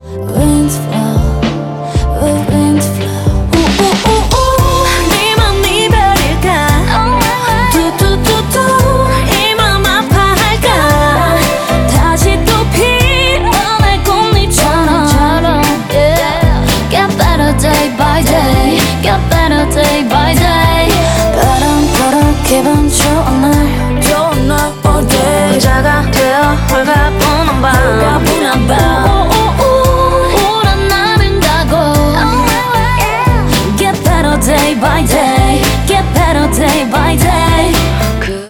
• Качество: 128, Stereo
громкие
женский вокал
грустные
мелодичные
K-Pop
Девчачий K-pop